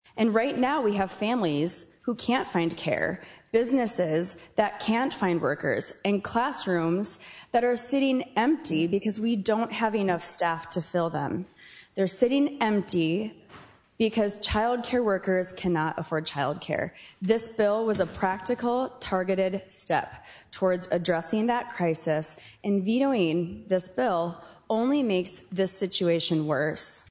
Healy emphasized empty classrooms result from staffing shortages.